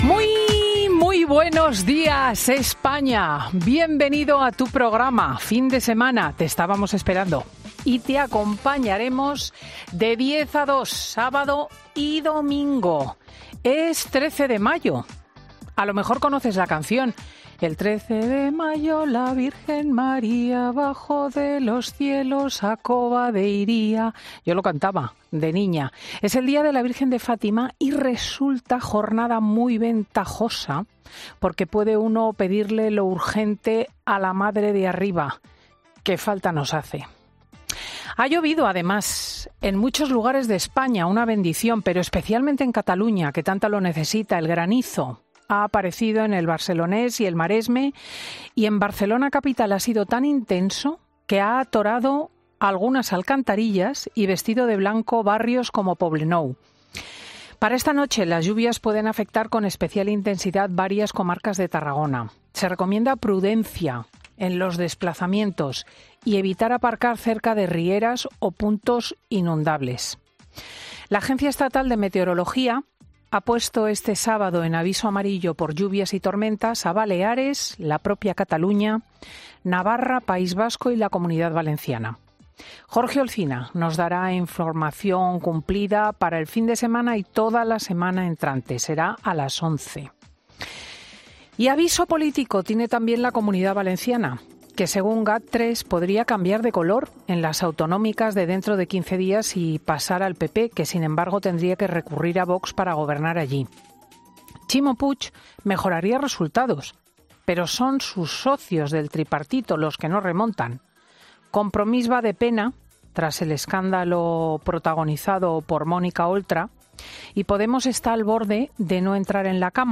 AUDIO: Ya puedes escuchar el monólogo de Cristina López Schlichting de este sábado 13 de mayo de 2023